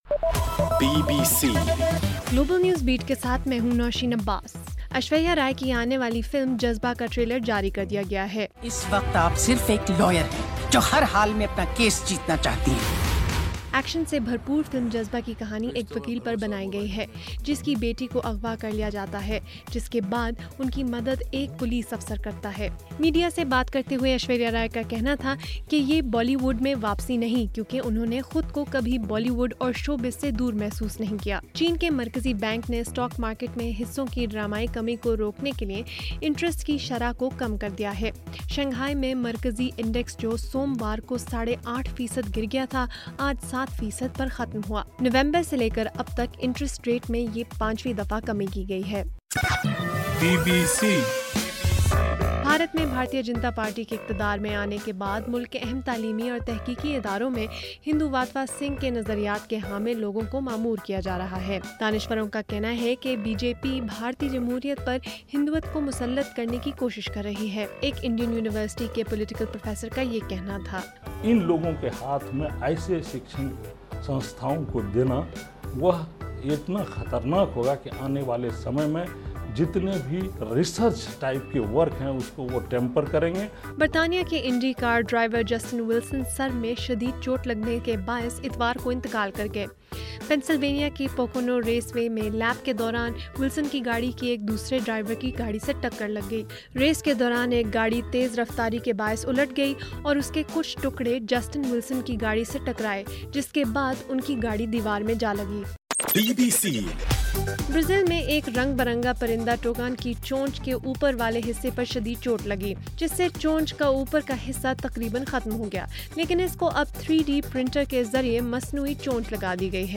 اگست 25: رات 10 بجے کا گلوبل نیوز بیٹ بُلیٹن